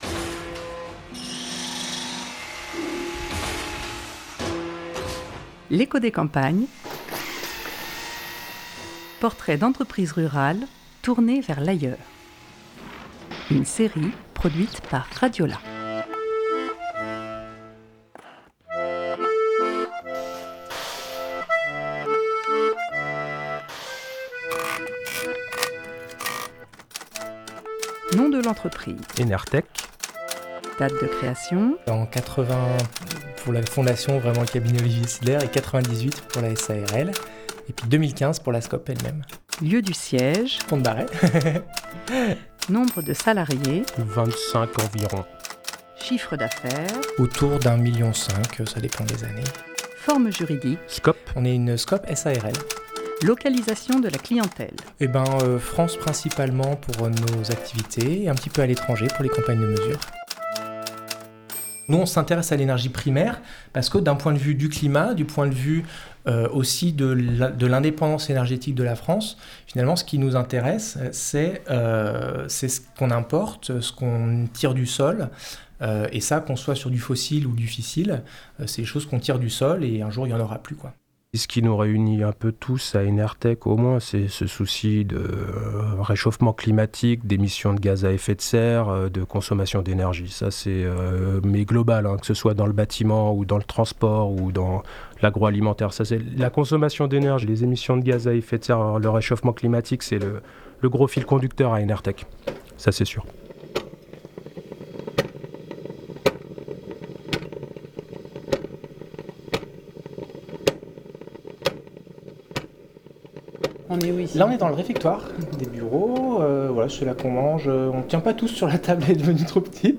3 mai 2021 12:05 | eco des campagnes, podcasts maison, reportage, territoire